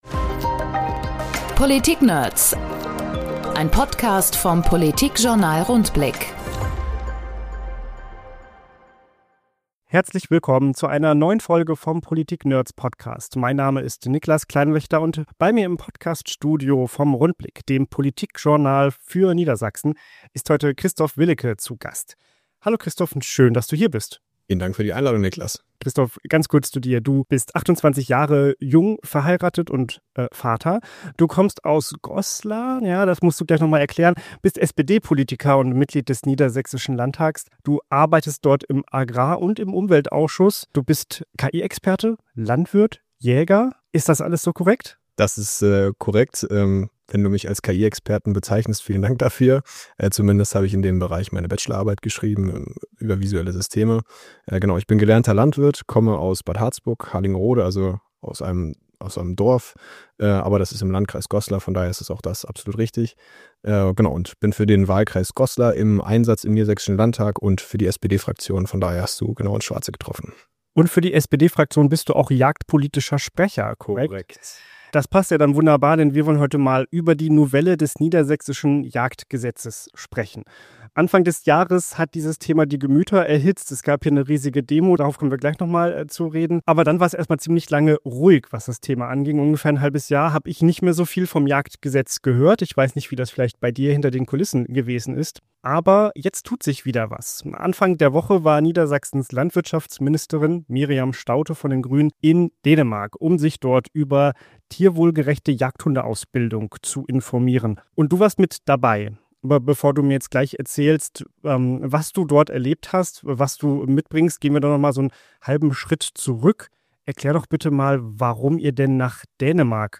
In unserem Podcast sprechen die Redakteure des Politikjournals Rundblick mit anderen Politikverrückten: Abgeordneten, Ministerinnen, Interessenvertretern und vielen mehr.